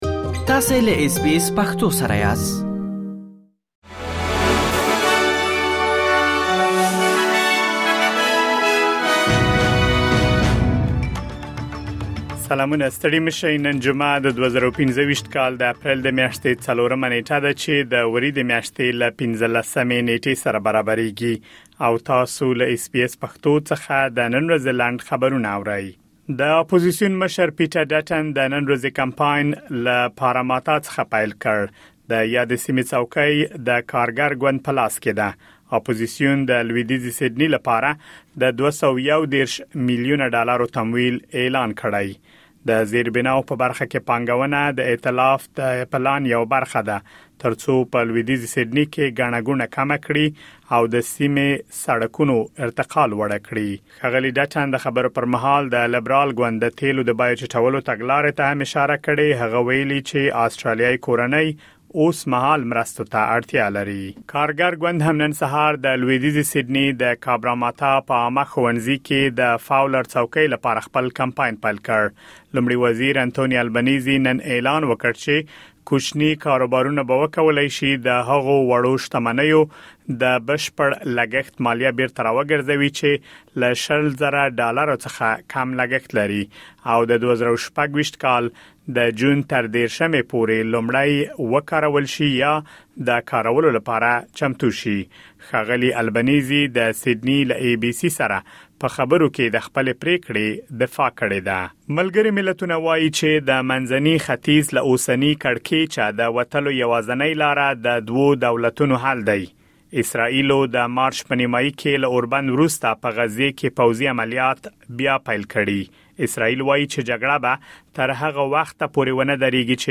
د اس بي اس پښتو د نن ورځې لنډ خبرونه | ۴ اپریل ۲۰۲۵